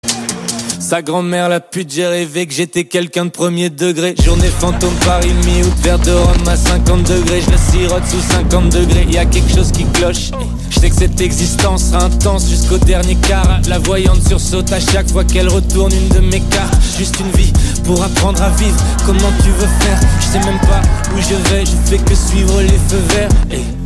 Catégorie: Rap - Hip Hop